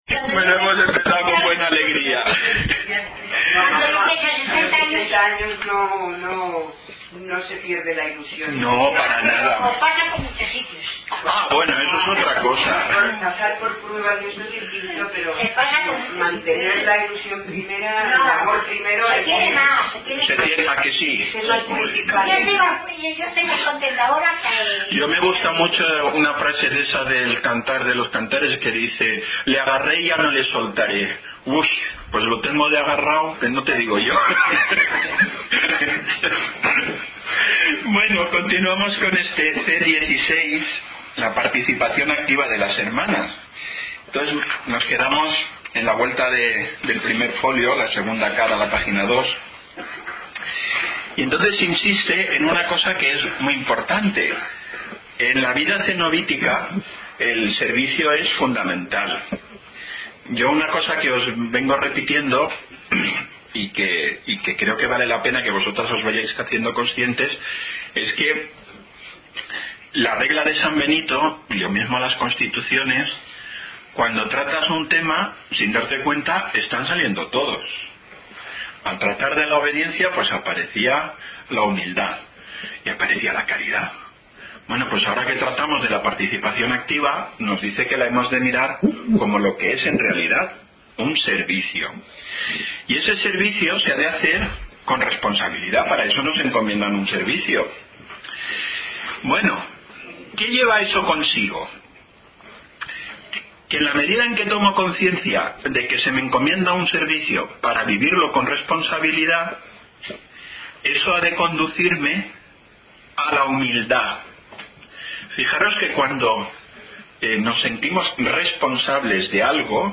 En Junio tuvimos el segundo encuentro formativo sobre la constitución número 16. Seguimos tratando el tema de la Participación activa de las hermanas.